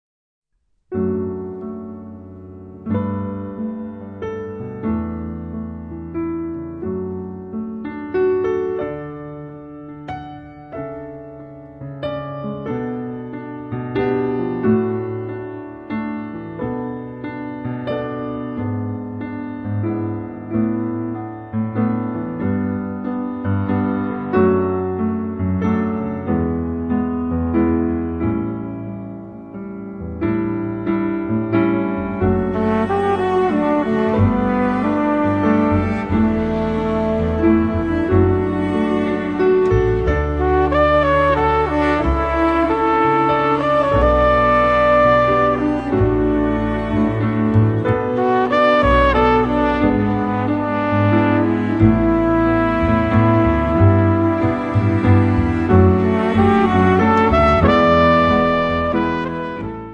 pianoforte
flicorno
contrabbasso
violoncello